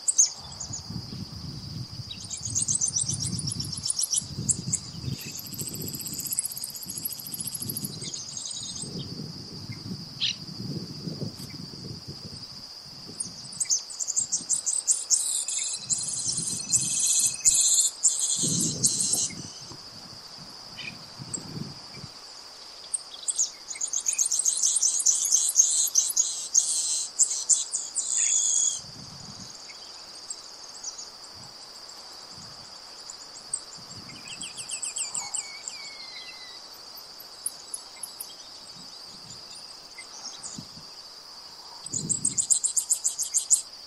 Misto (Sicalis luteola)
Nombre en inglés: Grassland Yellow Finch
Localización detallada: Camino Rural
Condición: Silvestre
Certeza: Vocalización Grabada